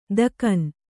♪ dakan